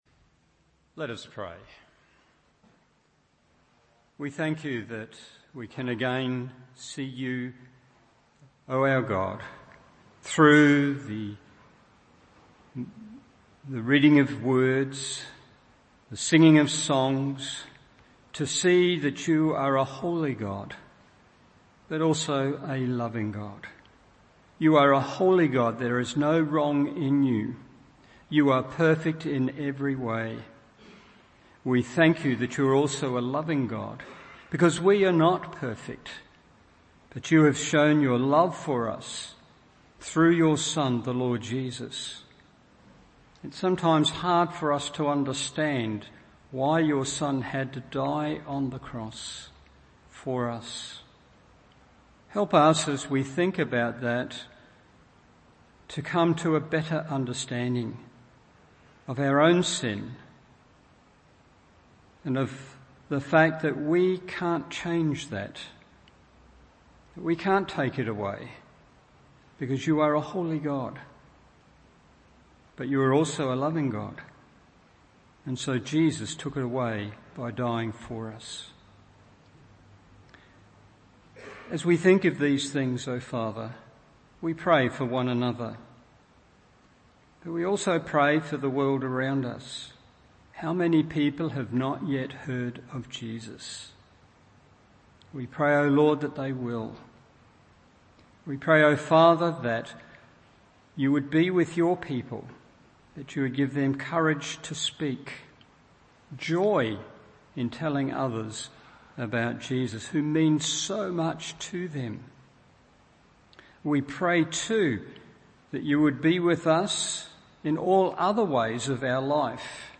Evening Service Meeting Jesus: Caesar’s Man in Charge – What are we to do with Jesus?